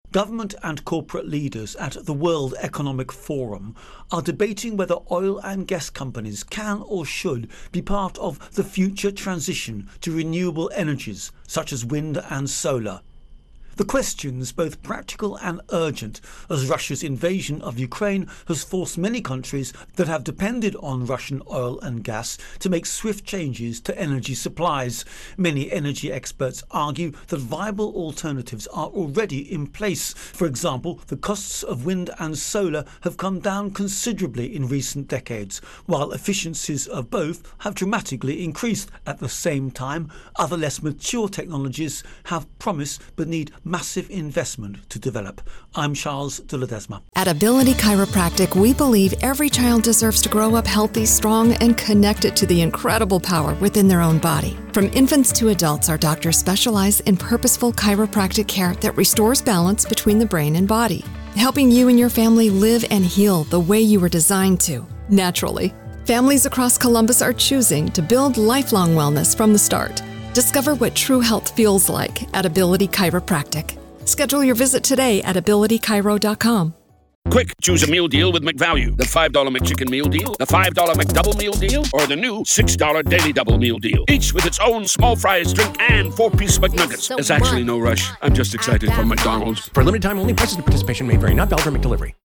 Davos Forum-Environment Intro and Voicer